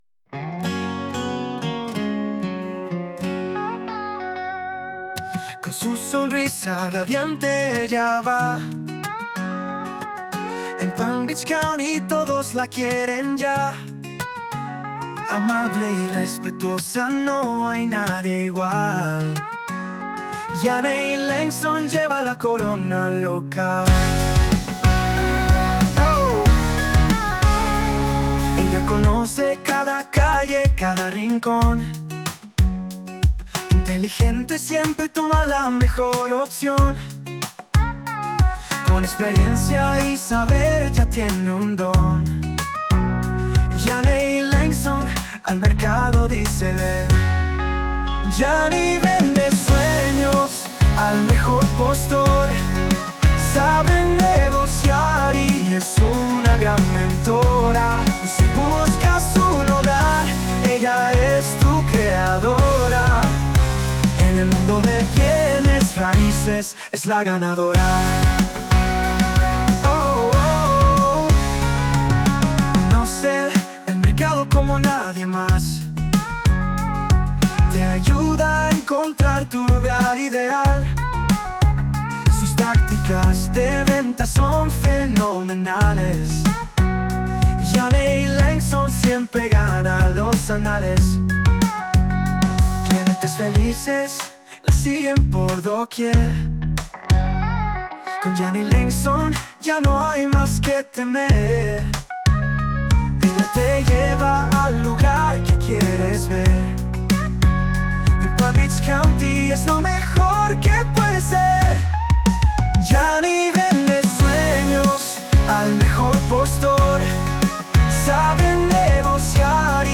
Music Genre: Pop Song (Male Singer)